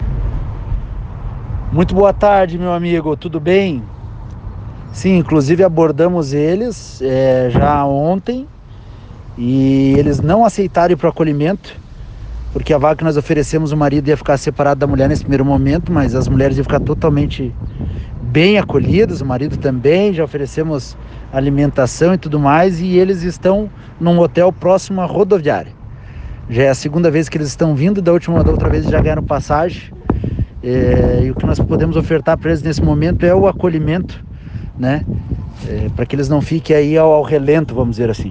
Ouça o que disse-me o Secretário: